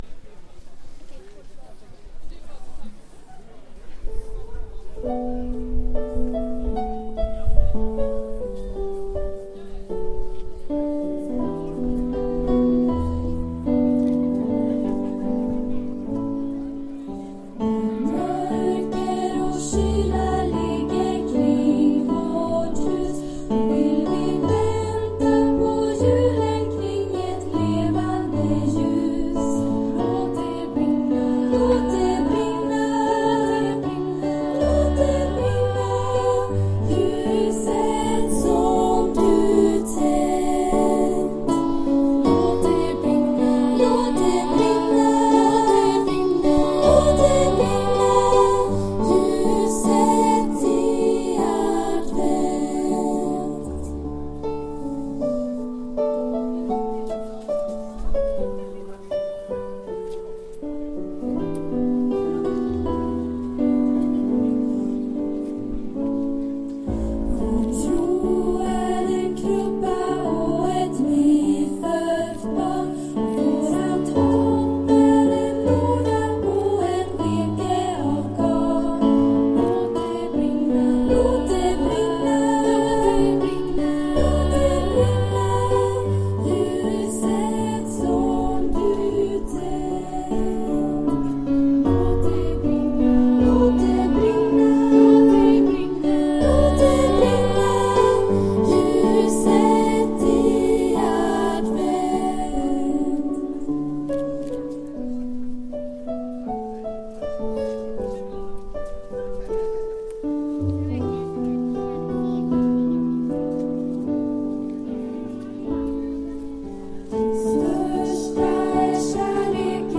Här nedan kan du lyssna på inspelningen från Stora Torget den 30 november 2015.